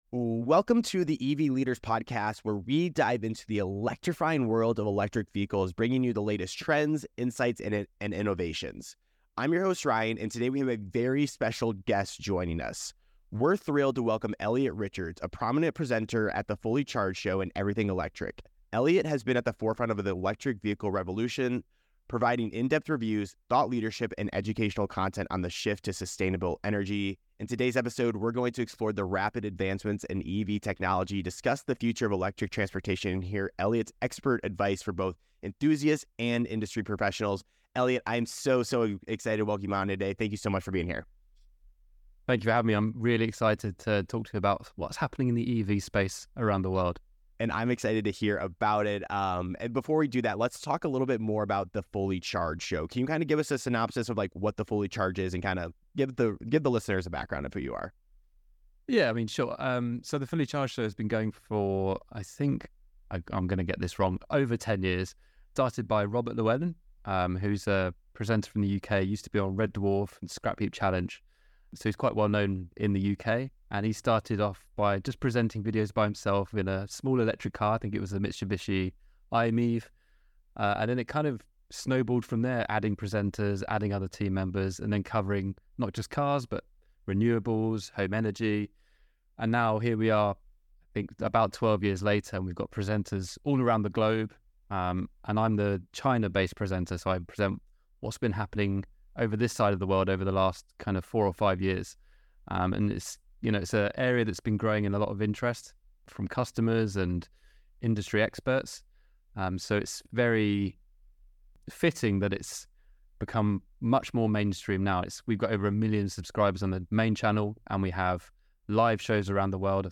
This conversation explores pivotal changes shaping a greener transportation landscape and highlights the technological marvels steering this evolution.